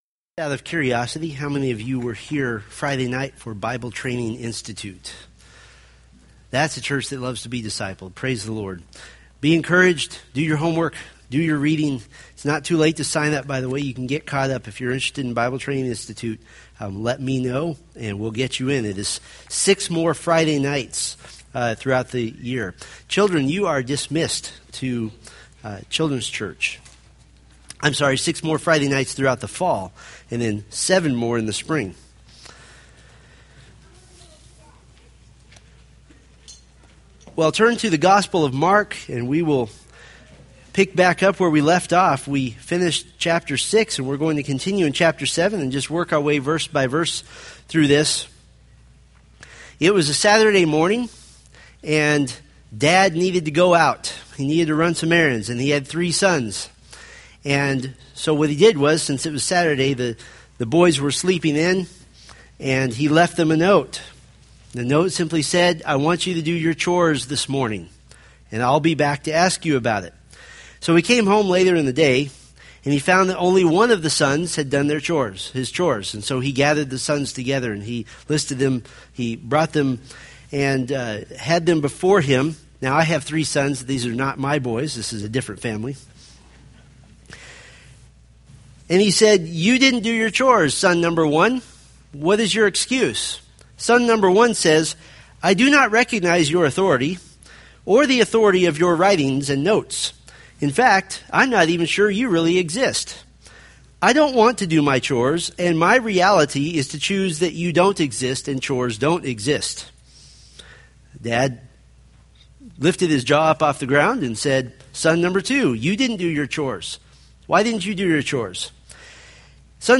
Mark Sermon Series